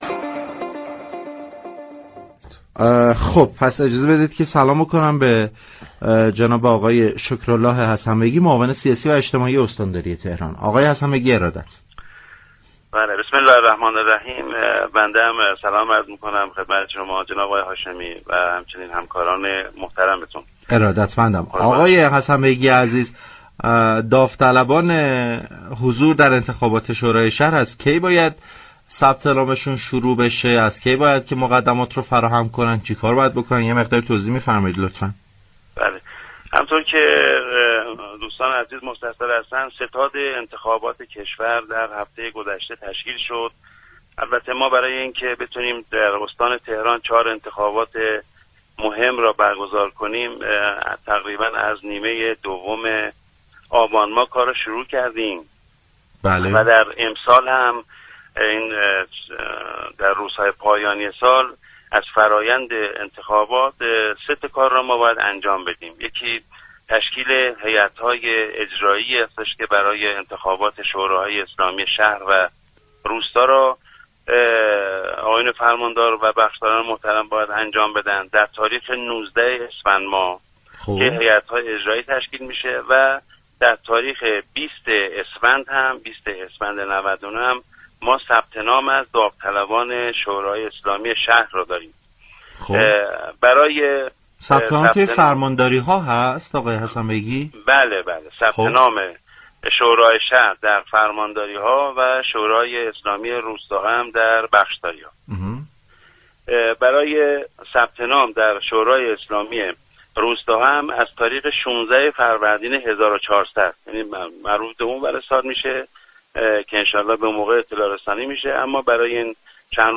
به گزارش پایگاه اطلاع رسانی رادیو تهران، شكرالله حسن بیگی در گفتگو با برنامه پارك شهر 26 بهمن ماه با اشاره به تشكیل ستاد انتخابات كشور از هفته گذشته و تمهیدات وزارت كشور برای برگزاری انتخابات اسفندماه اظهار داشت: برای اینكه بتوانیم در استان تهران 4 انتخابات را برگزار كنیم فعالیت ستاد انتخابات از نیمه دوم آبانماه آغاز شده است.